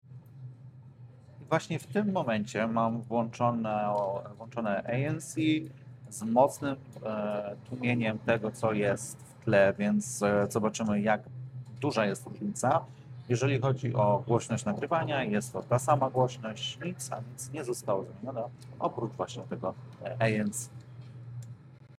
Próbka surowego dźwięku prosto z mikrofony – nagrana za pomocą iPhone 16 Pro Max:
HollyLand-Lark-A1-z-ANC.mp3